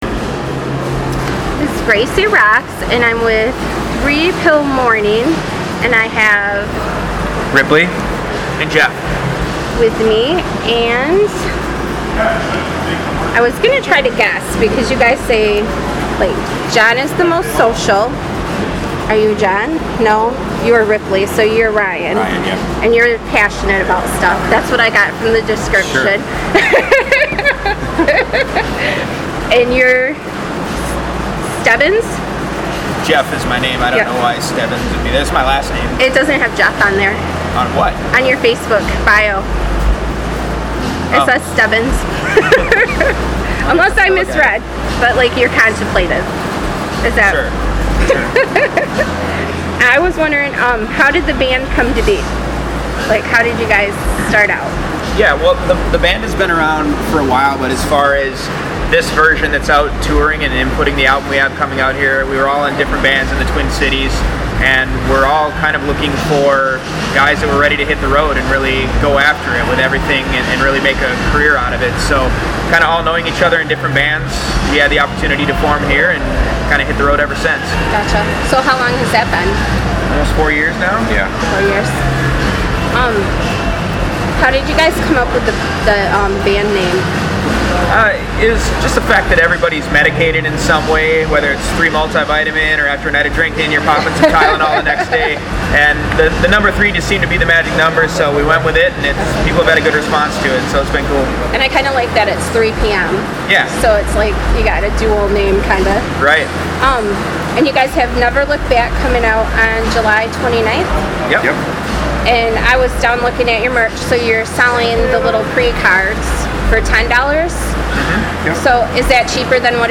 I had the opportunity to make the drive down to St. Andrews Hall and interview 3 Pill Morning!